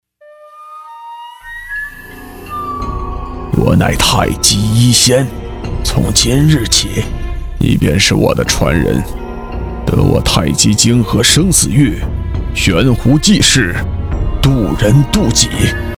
【角色】古装老人